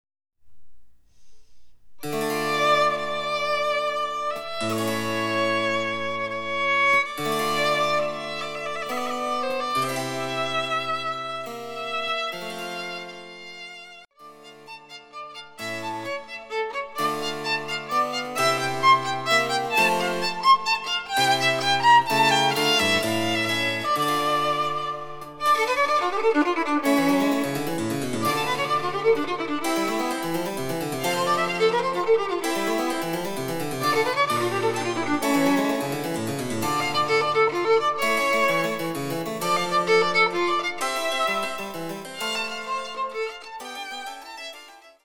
曲の形としては短い低音旋律が何度も繰り返される上でいろいろな変奏をつぎつぎと連ねていきます。
リコーダーによる演奏　（Ｃ−３）
チェンバロ（電子楽器）